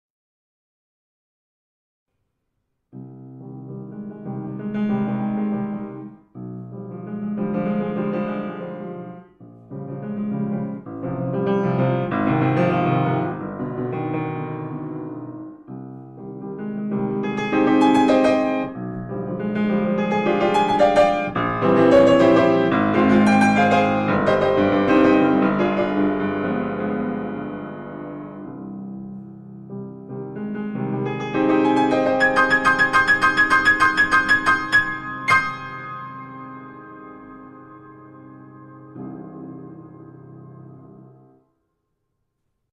nauka gry na pianinie online